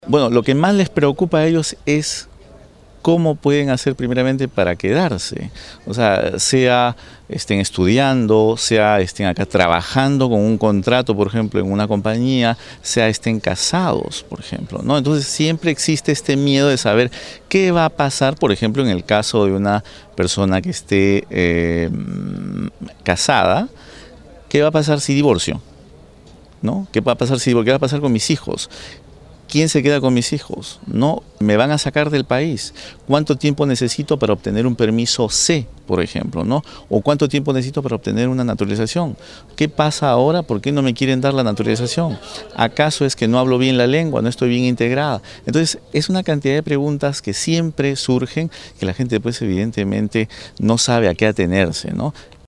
en entrevista con swissinfo.